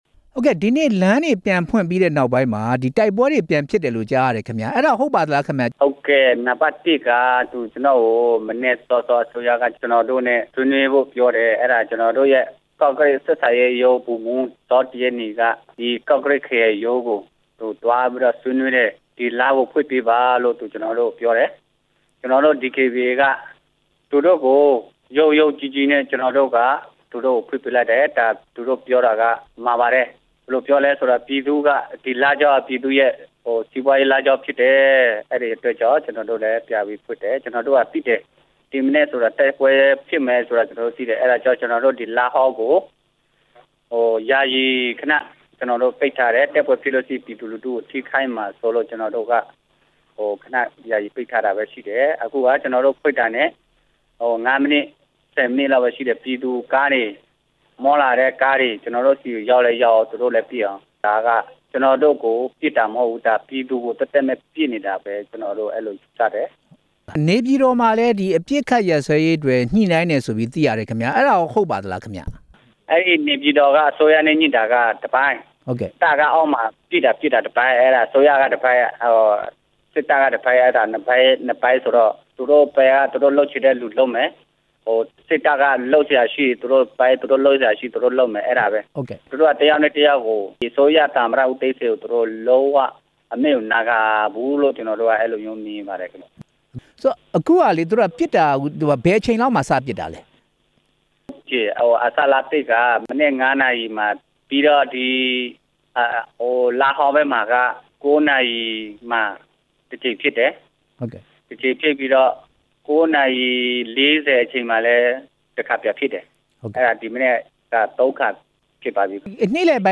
ဆက်သွယ်မေးမြန်းခဲ့တာ